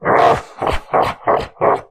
spawners_mobs_balrog_neutral.3.ogg